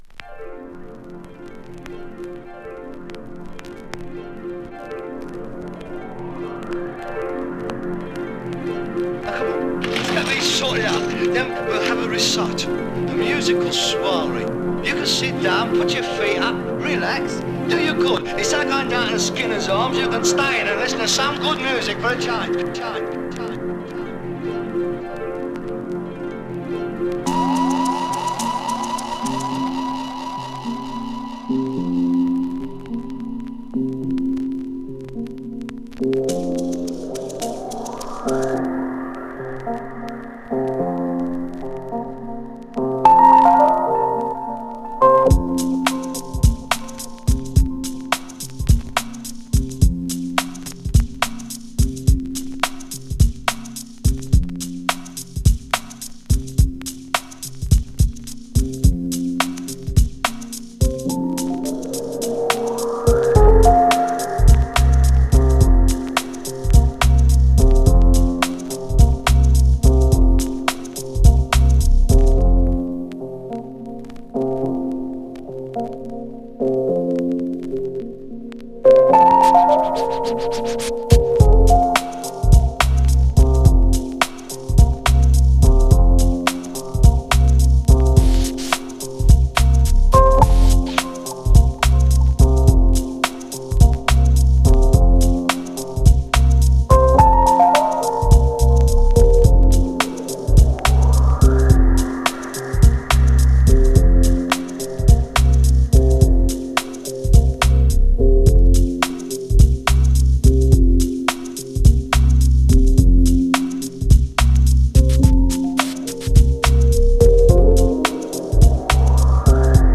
2. > HIPHOP
3. > JAZZY BREAK/ELECTRONICA/ABSTRACT